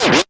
TEARIN.wav